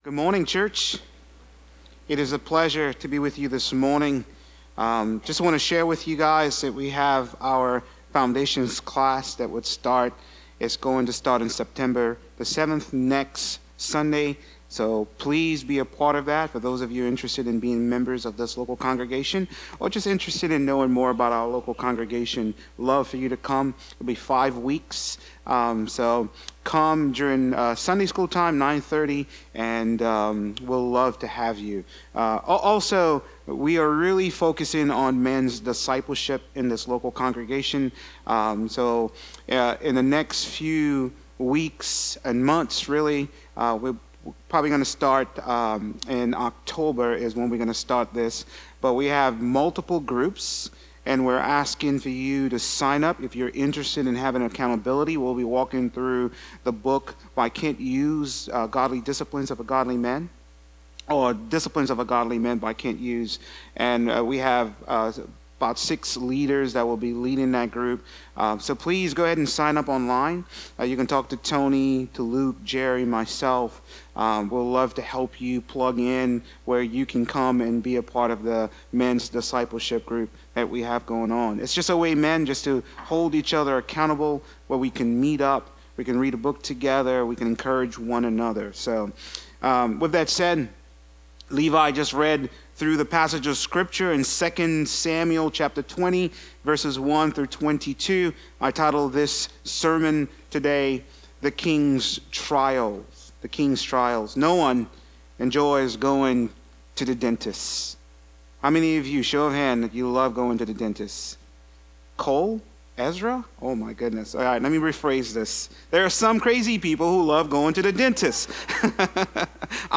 A message from the series "The Book of 2 Samuel."